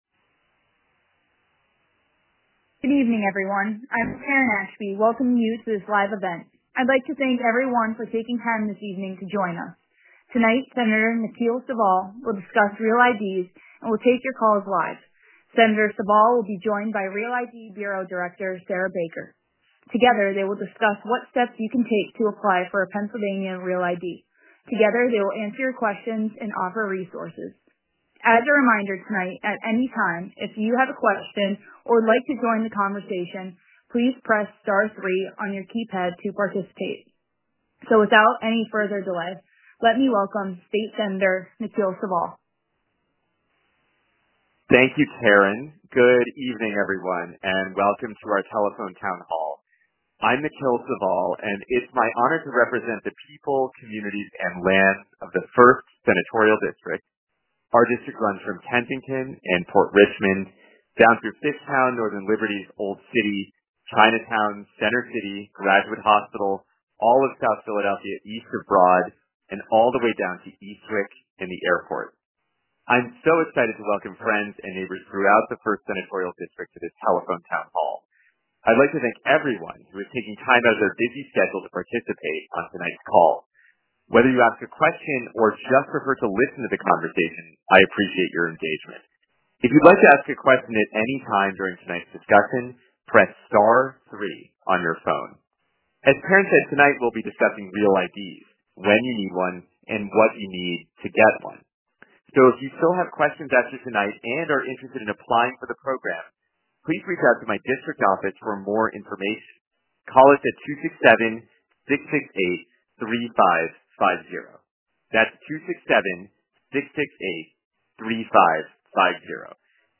Senator Saval hosted a Telephone Town Hall on February 5, 2026 to discuss the the LIHEAP program and how you can get help with your energy bills.